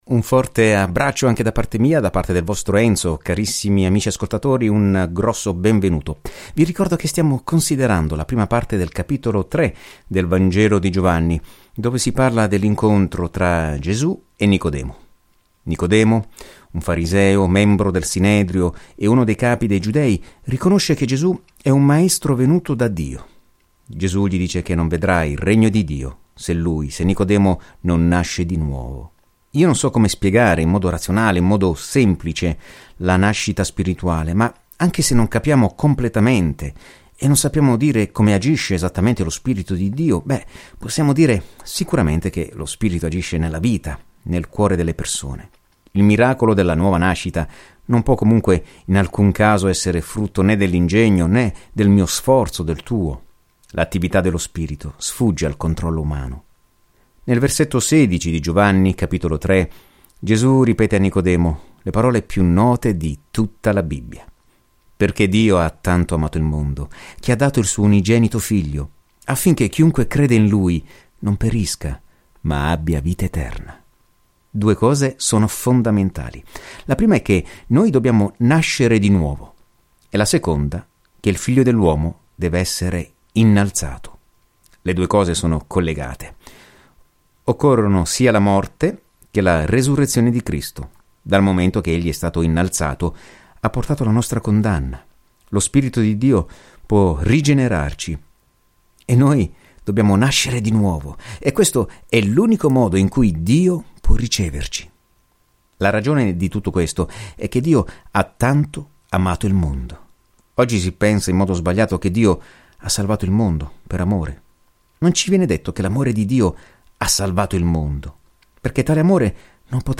Scripture John 3:16-36 John 4:1 Day 7 Start this Plan Day 9 About this Plan La buona notizia spiegata da Giovanni è unica rispetto agli altri Vangeli e si concentra sul motivo per cui dovremmo credere in Gesù Cristo e su come avere vita in questo nome. Viaggia ogni giorno attraverso Giovanni mentre ascolti lo studio audio e leggi versetti selezionati della parola di Dio.